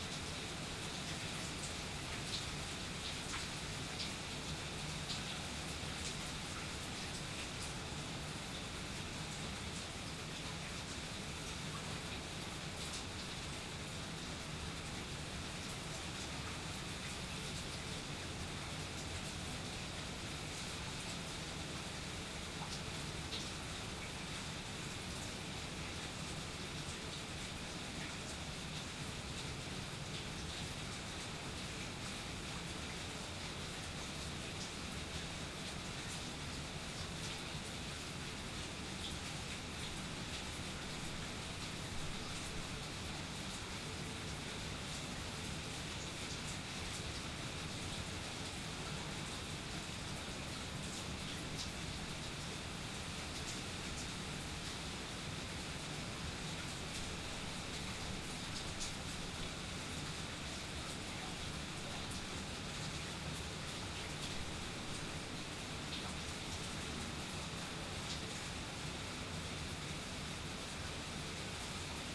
Weather Evening Suburbs Rainfall Concrete 01 BH2N_ambiX.wav